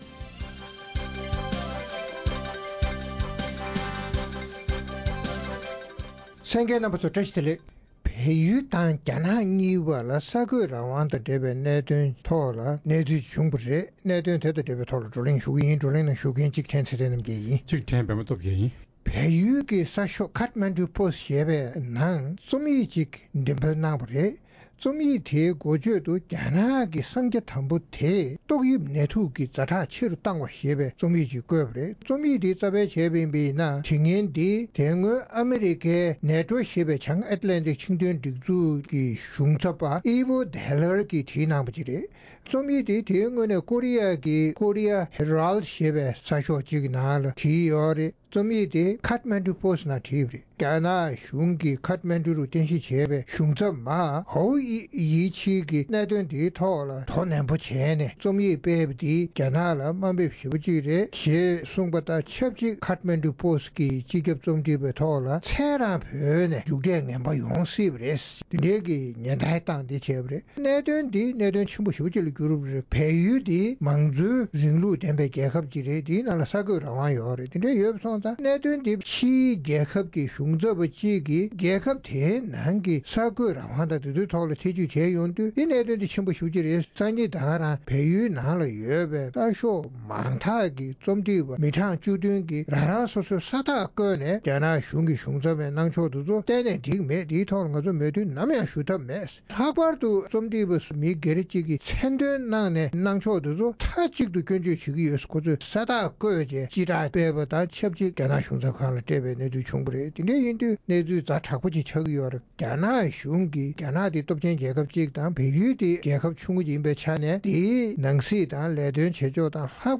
བལ་ཡུལ་དུ་བཅའ་བཞུགས་རྒྱ་ནག་གཞུང་ཚབ་ནས་བལ་ཡུལ་གྱི་གསར་འགོད་རང་དབང་ལ་ཐེ་གཏོགས་དང་སྡིགས་སྐུལ་བཏང་བ་ལ་སོགས་པའི་གནད་དོན་སྐོར་རྩོམ་སྒྲིག་པ་རྣམས་ཀྱིས་གླེང་མོལ་གནང་བ་གསན་རོགས་གནང་།།